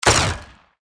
pistolplasma_fire_3d.mp3